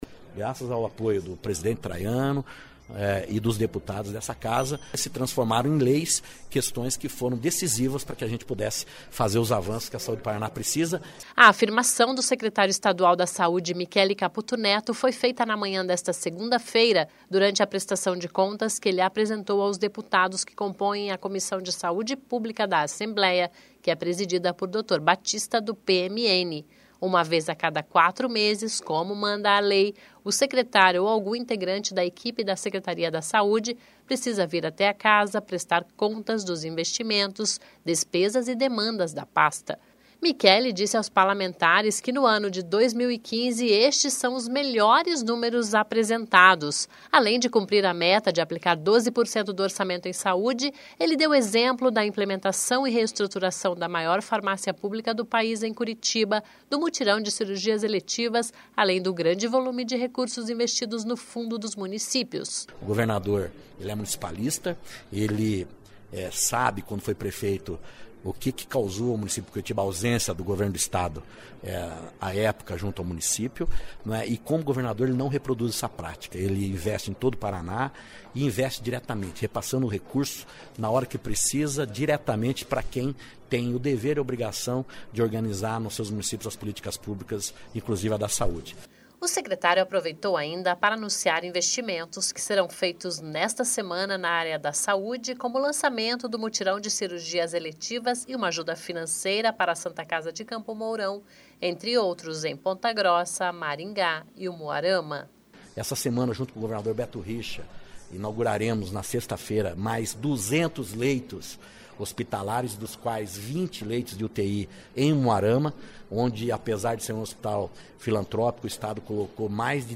(Descrição do áudio))Começa com sonora do Michele Caputo Neto, falando que a Assembleia foi fundamental para os bons números apresentados nesta segunda.